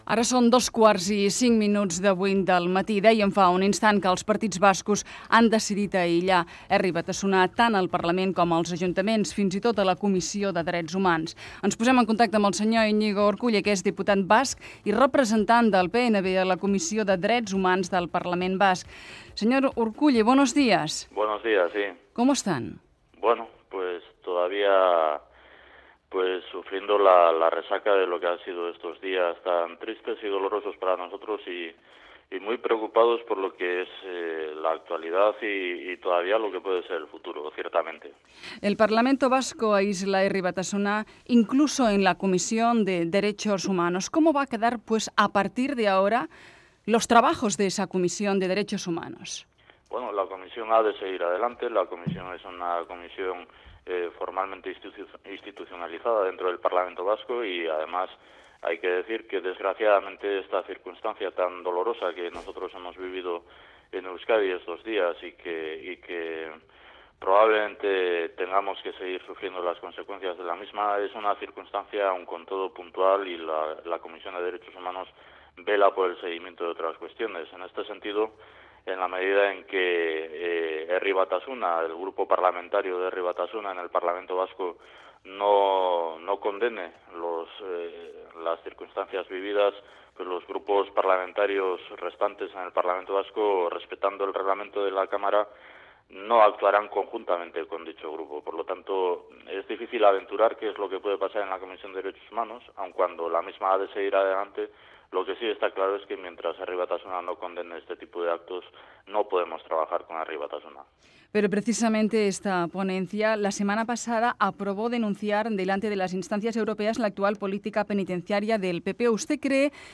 Fragment d'una entrevista al polític Iñigo Urkullu del Partit Nacionalista Basc, després que el Parlament basc aillés el grup parlamentari del partit Herri Batasuna, arran de l'assassinat del polític del Partido Popular Miguel Ángel Blanco.
Info-entreteniment